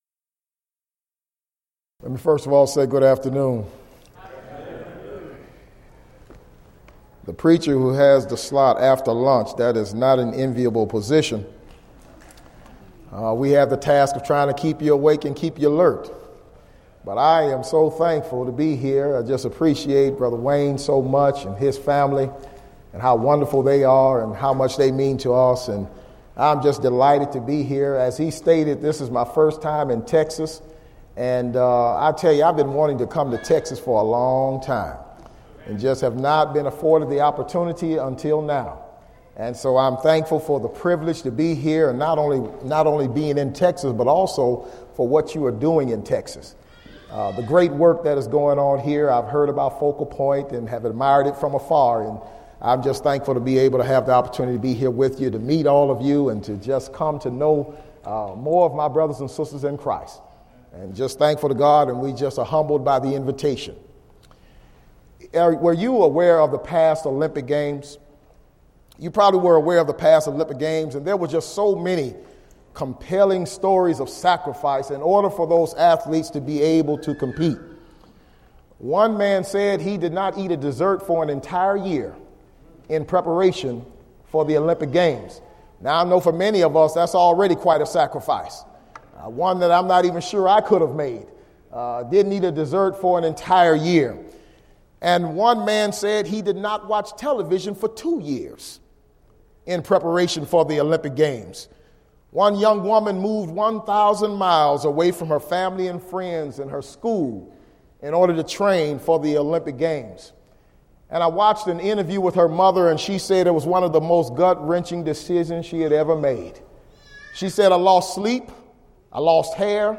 Event: 2014 Focal Point Theme/Title: Preacher's Workshop
lecture